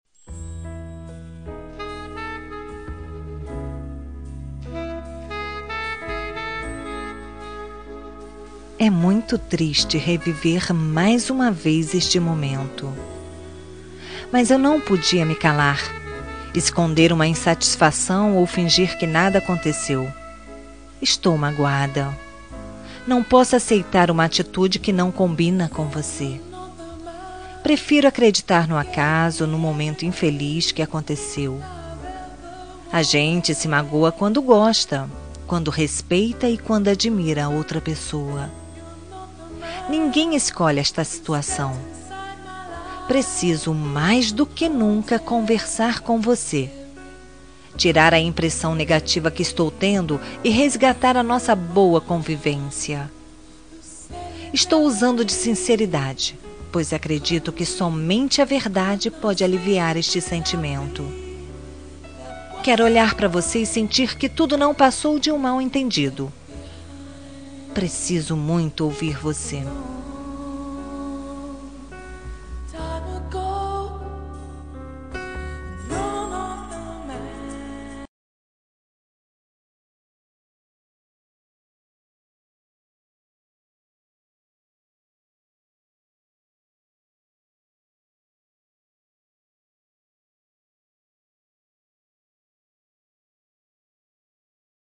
Toque para Não Terminar – Voz Feminina – Cód: 461 – Você me Magoou
461-voce-me-magoou-fem.m4a